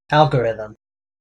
dengngen) AL-gə-ri-dhəm) ket isu ti nagsasaruno a pamay-anipara iti panagpattapatta.
En-us-algorithm.ogg